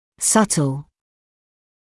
[‘sʌtl][‘сатл]тонкий; едва различимый